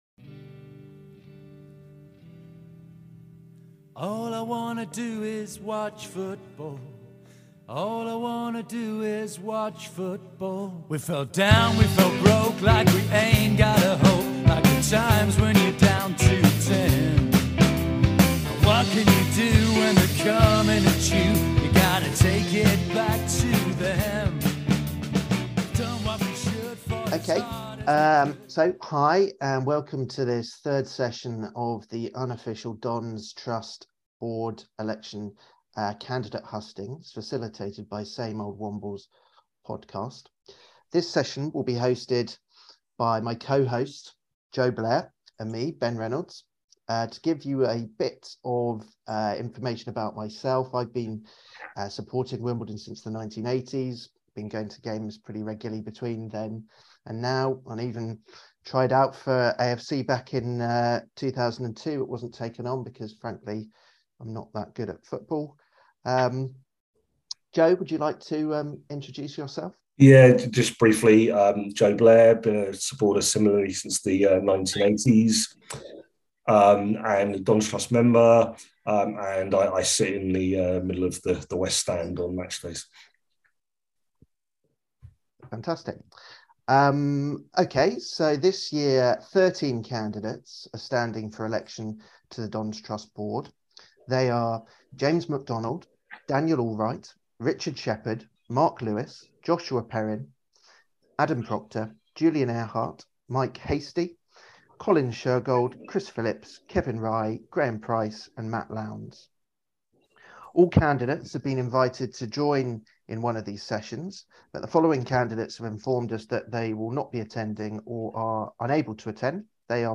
Session 3 of 3 unofficial hustings events in the run up to the 2022 Dons Trust Board Elections.
If the audio loops back to the start of the session at 26mins in please redownload for corrected file